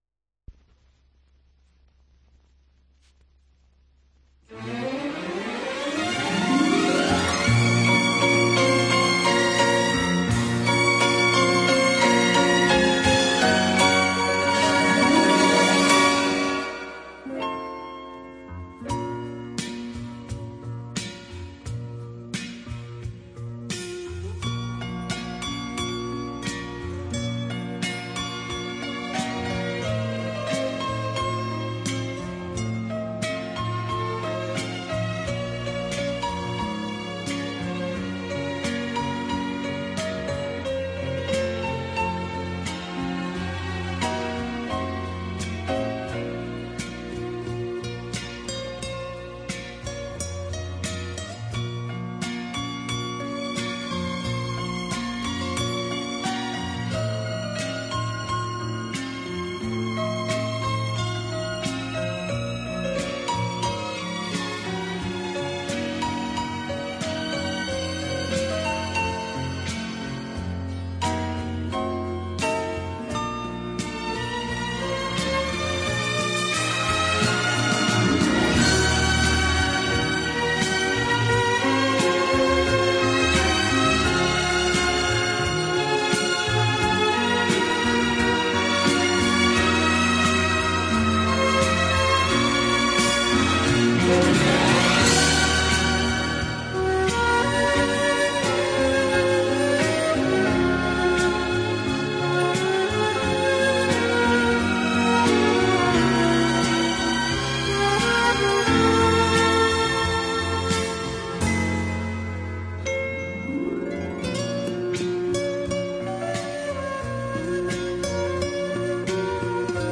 Жанр: Pop/Instrumental Orchestra